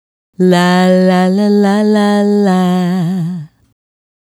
La La La 110-F#.wav